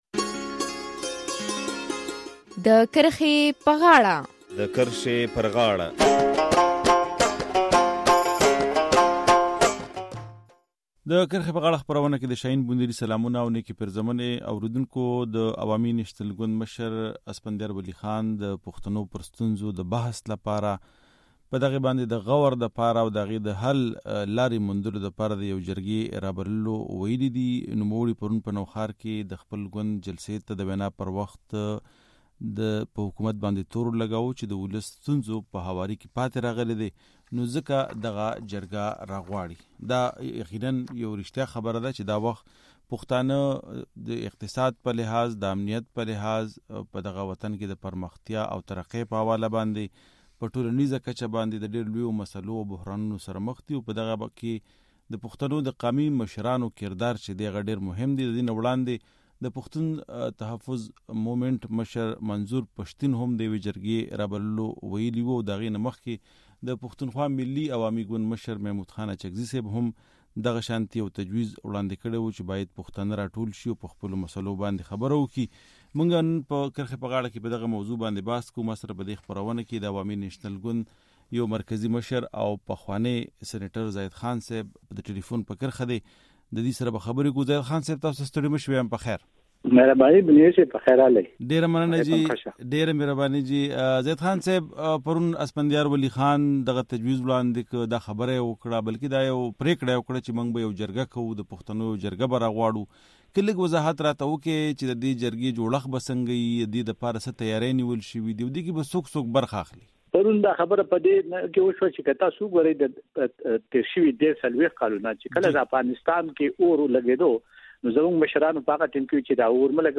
د کرښې پر غاړه کې پرې بحث کوو.